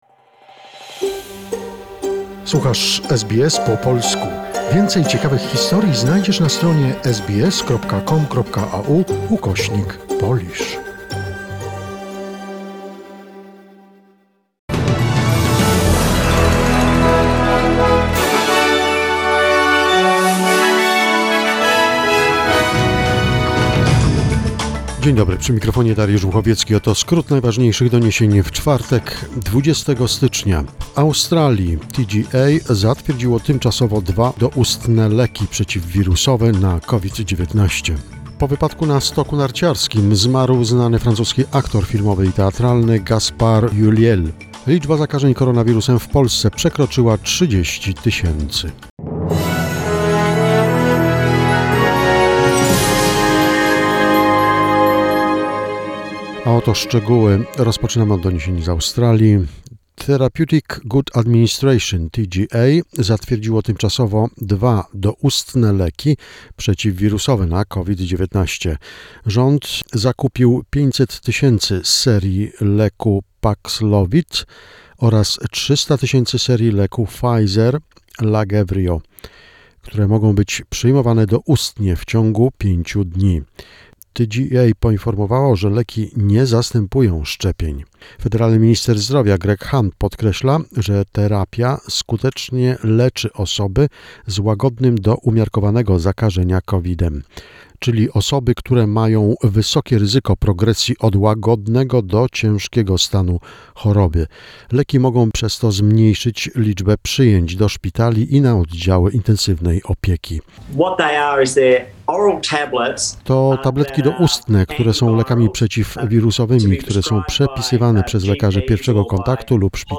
SBS News Flash in Polish, 20 January 2022